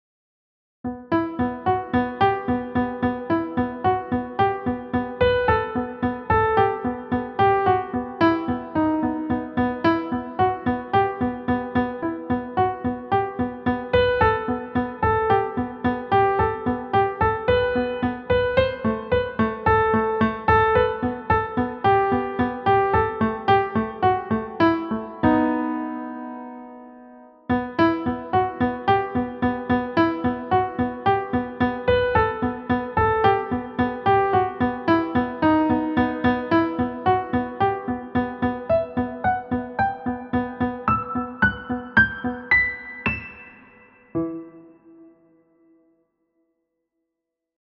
Time signature: 4/4, BPM ≈ 220
• Developing confidence at faster tempos